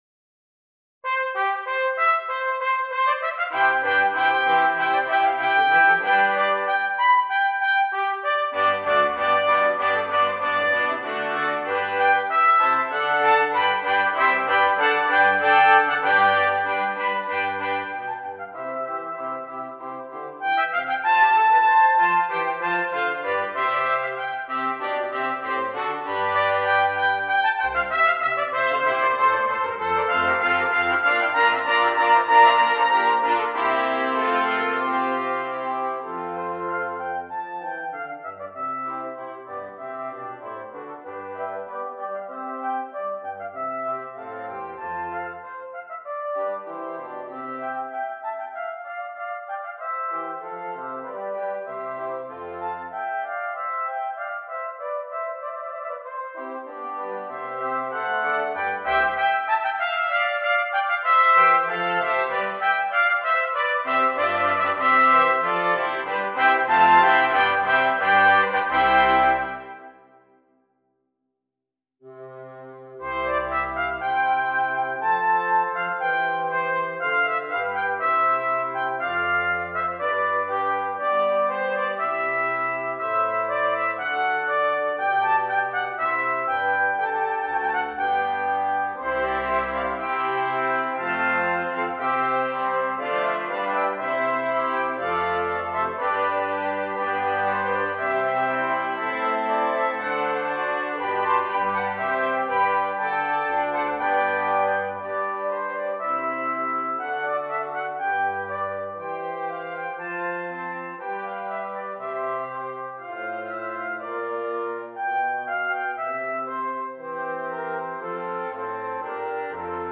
7 Trumpets and 2 Bass Clef Instruments